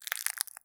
High Quality Footsteps
STEPS Glass, Walk 09.wav